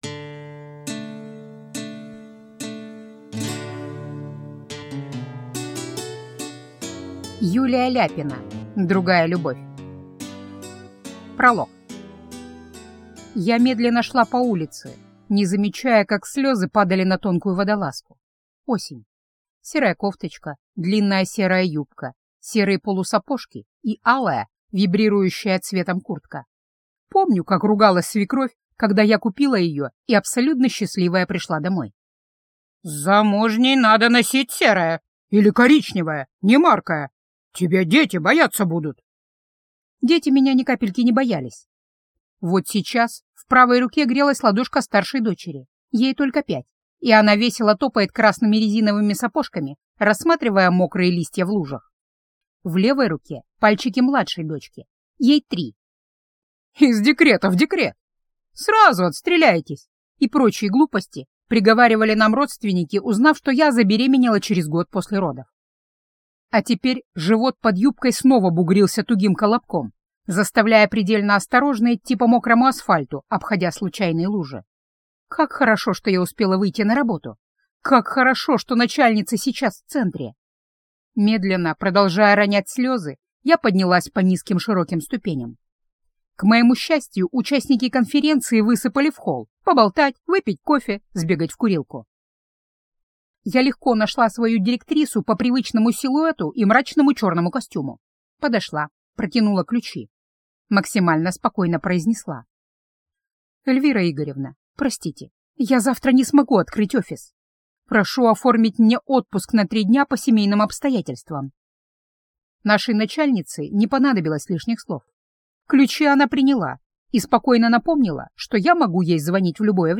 Аудиокнига Другая любовь